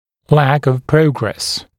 [læk əv ‘prəugres][лэк ов ‘проугрэс]недостаток прогресса, недостаочно положительное развитей процесса